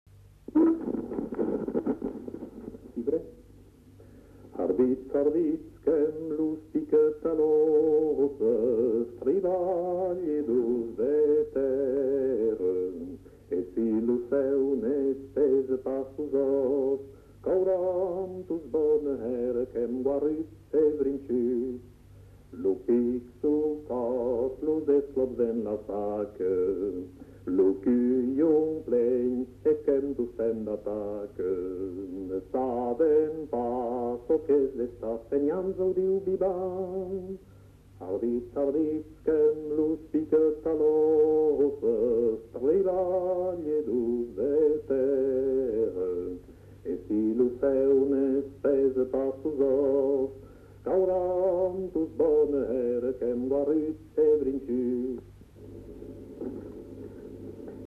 [Brocas. Groupe folklorique] (interprète)
Aire culturelle : Marsan
Genre : chant
Type de voix : voix d'homme
Production du son : chanté
Description de l'item : fragment ; 1 c. ; refr.